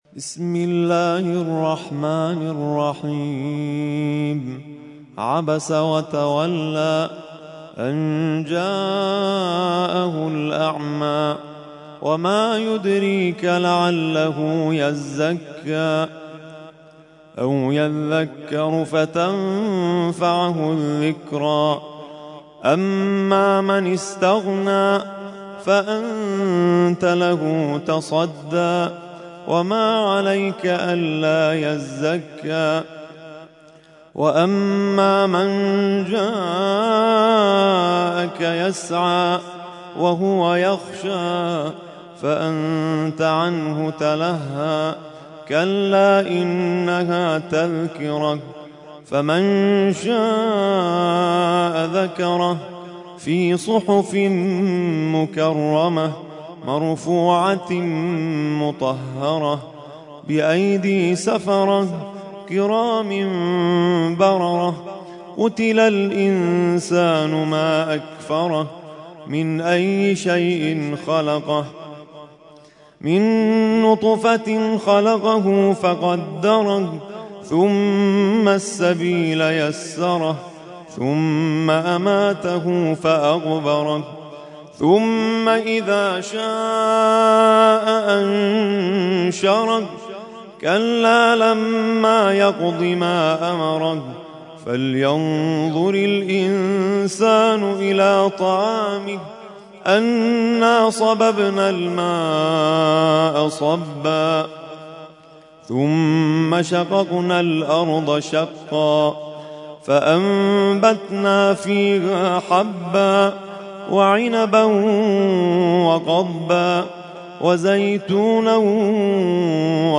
ترتیل خوانی جزء ۳۰ قرآن کریم در سال ۱۳۹۲